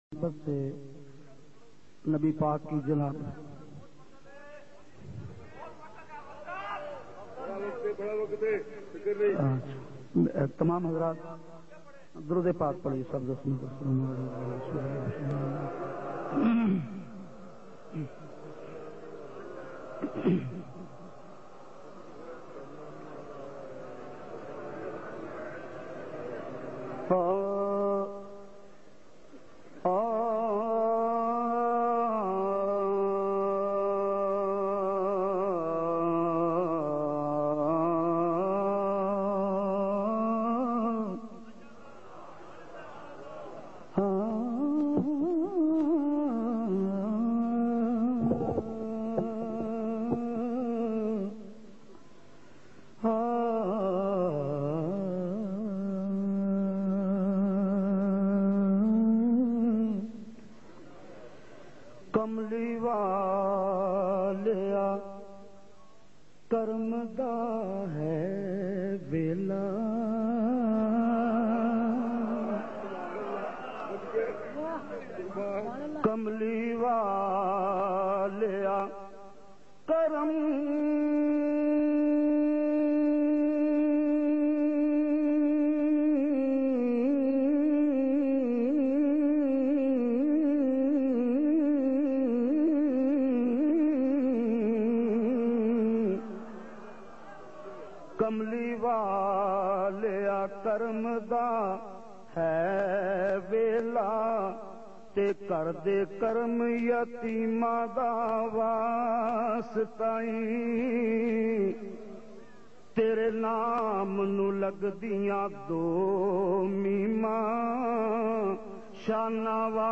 in a Heart-Touching Voice